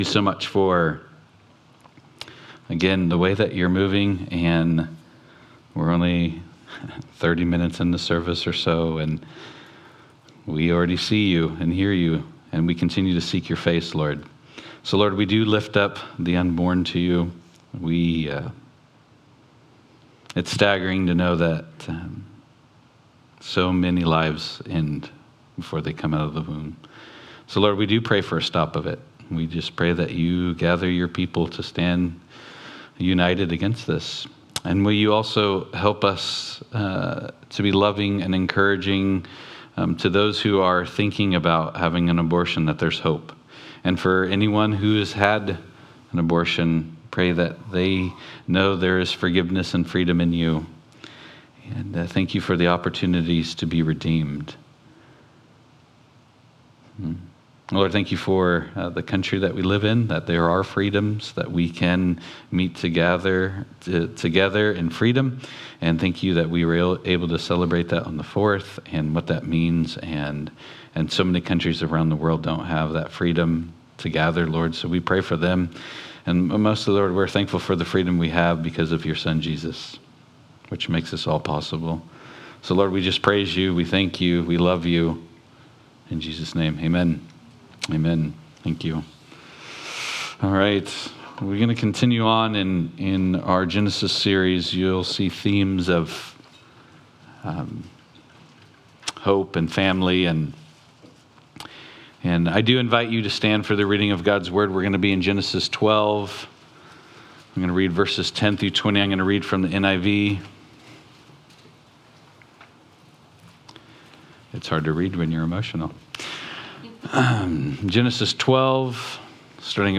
Genesis Service Type: Sunday Morning « Genesis-In the Beginning